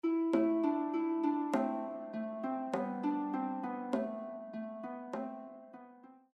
traditional Swedish song